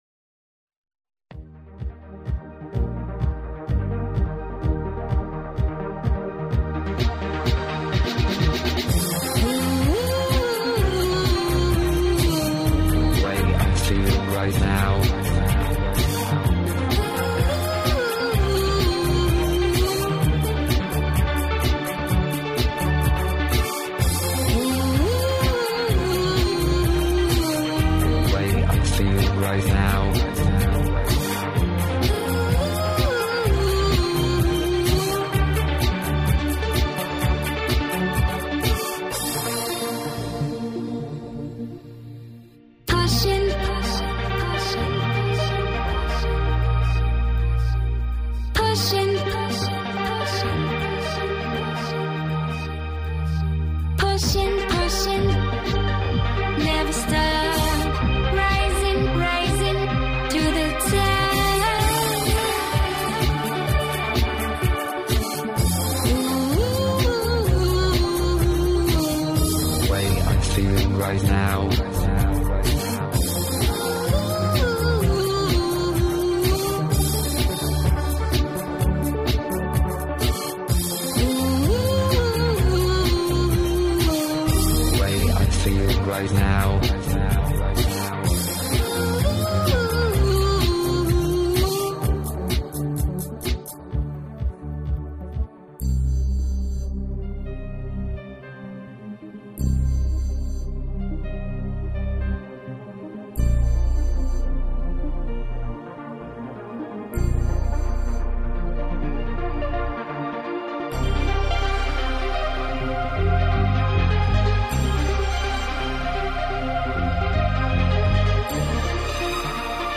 dance/electronic
Club Anthems